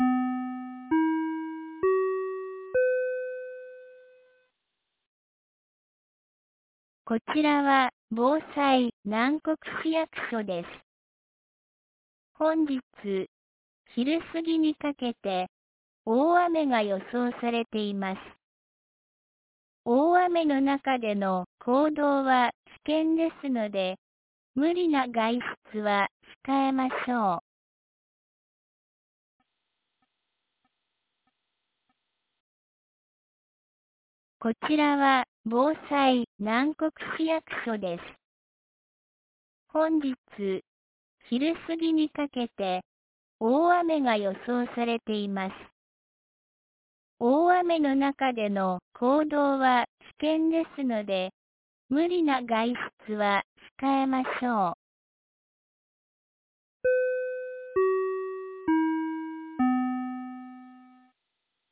2024年05月28日 09時46分に、南国市より放送がありました。